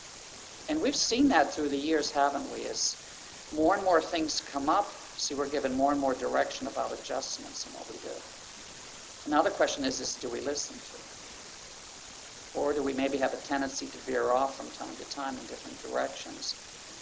I have a talk I recorded (digital recorder a few feet from a speaker) and have some noise from a little bit low signal.
Here is a clip with the speaker talking. As you can hear, the noise is distracting.
clip with speaker.wav